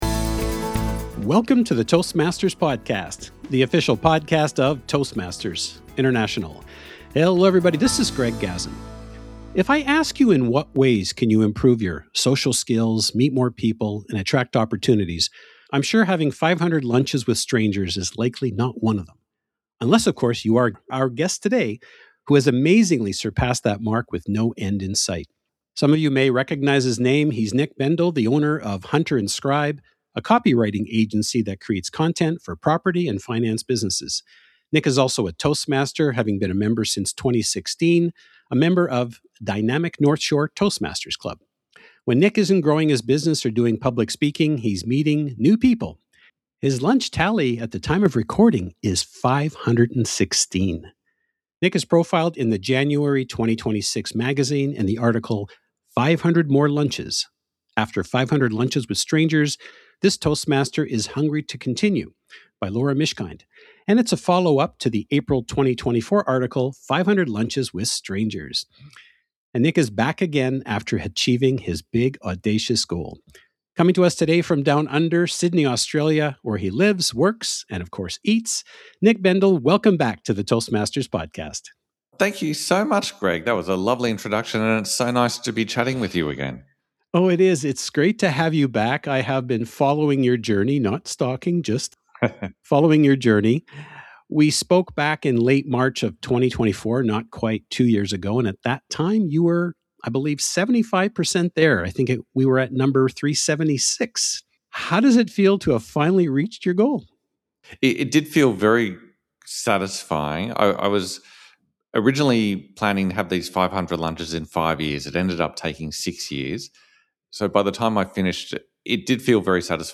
In this follow-up to our first interview, you will hear: